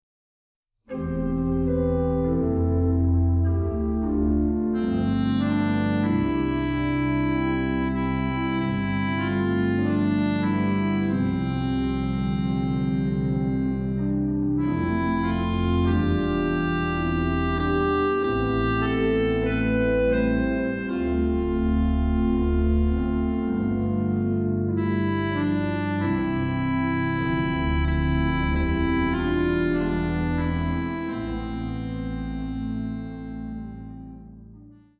Orchester